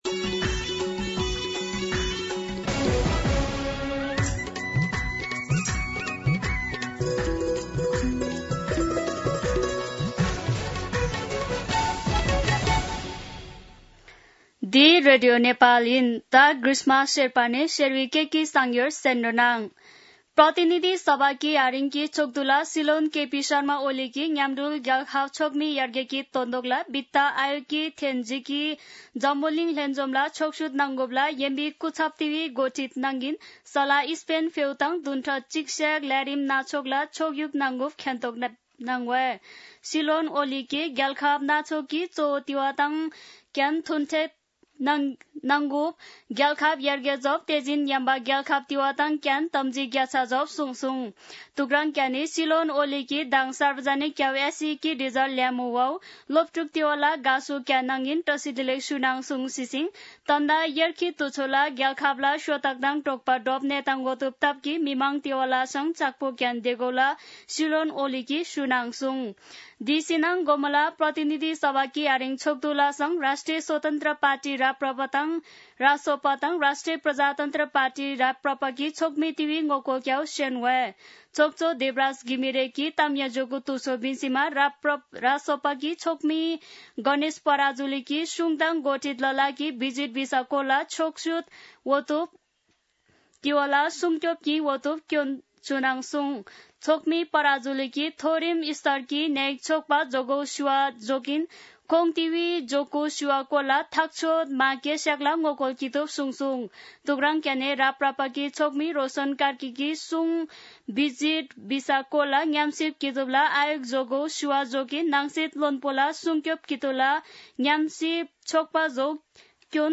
शेर्पा भाषाको समाचार : १४ असार , २०८२
Sherpa-News-3.mp3